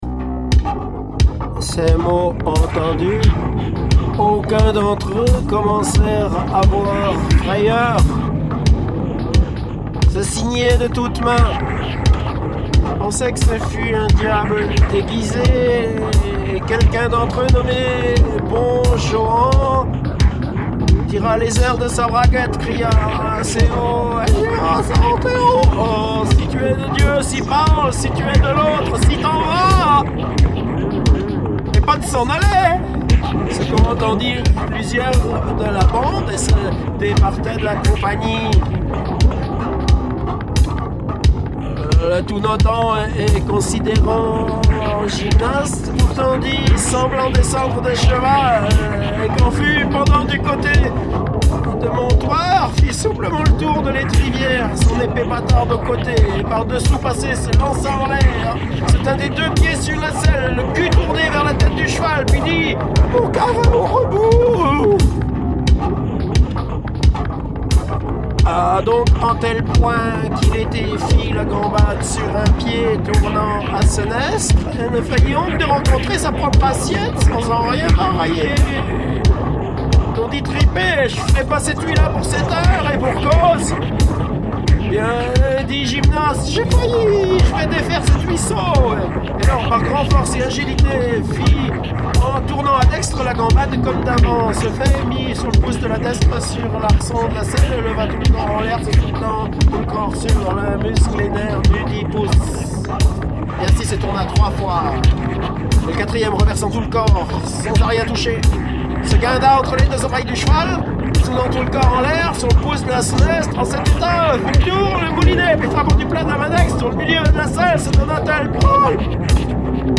Enregistrements directs sur ordinateur via Ableton Live, ou lectures publiques si indiqué.